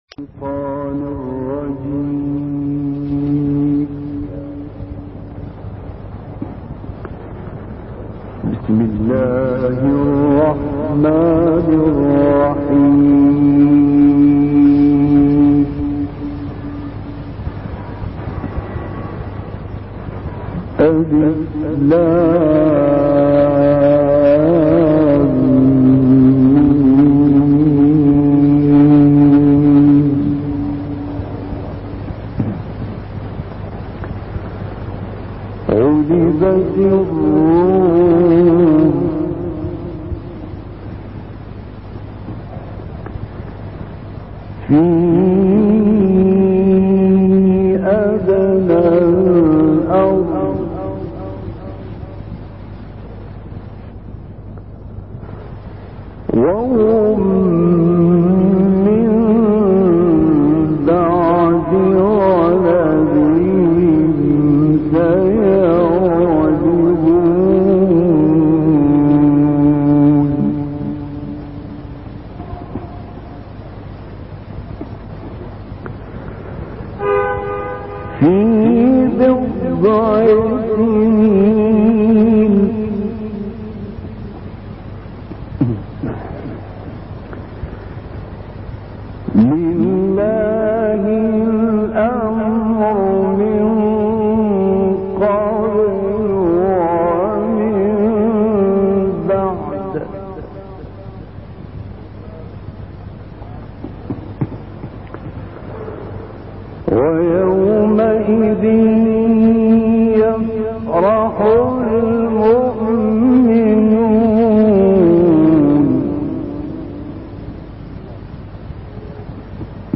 تلاوت سوره روم با صوت «راغب مصطفی غلوش»
گروه شبکه اجتماعی ــ تلاوت آیاتی از سوره‌ مبارکه روم که شامل آیه‌های بهاریست، با صوت راغب مصطفی غلوش را می‌شنوید.